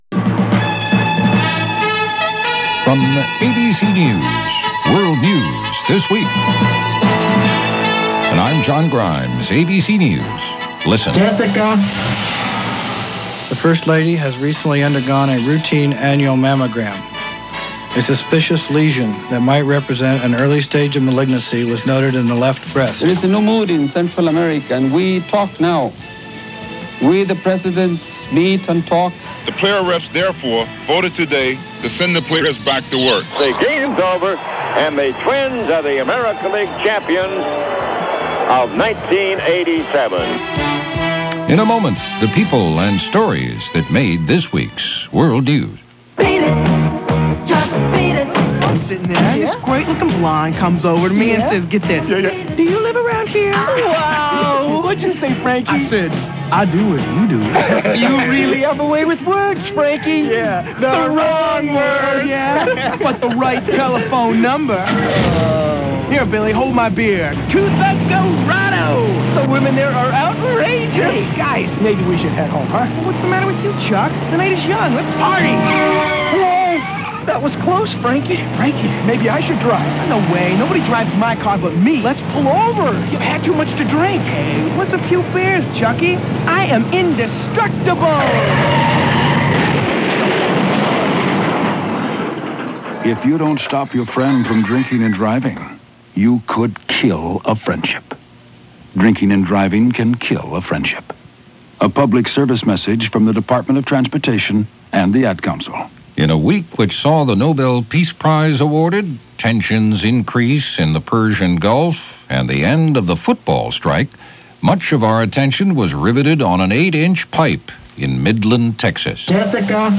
October 18, 1987 - The First Lady faces Cancer - A Trapped Toddler in Texas - The Ugliest Tie in America - News of this week in history.